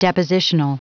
Prononciation du mot depositional en anglais (fichier audio)
Prononciation du mot : depositional